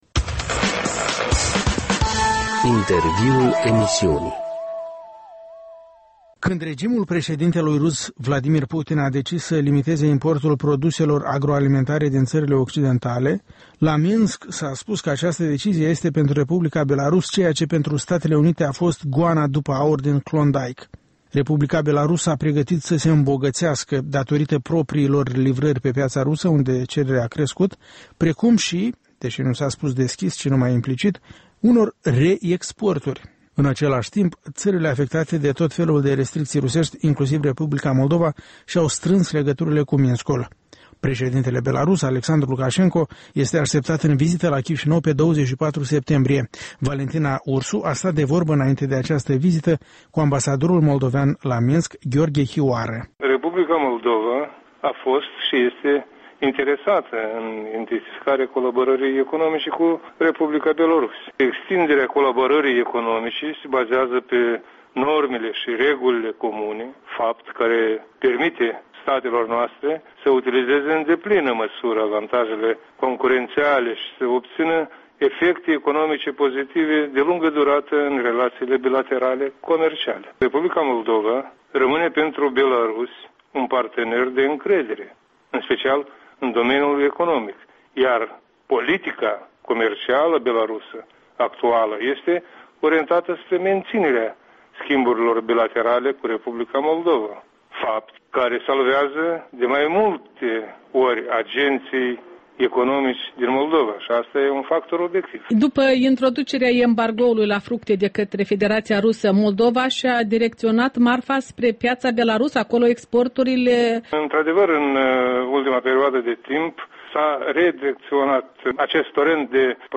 Un interviu cu amabsadorul R. Moldova la Minsk